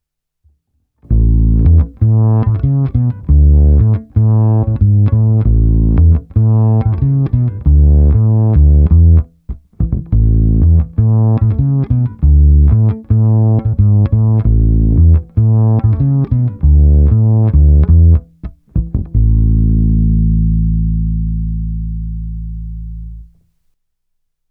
Protáhnul jsem basičku i skrz kompresor a preamp Darkglass Microtubes X Ultra se zapnutou simulací aparátu. Je to zvuková lahoda.
Ukázka se simulací aparátu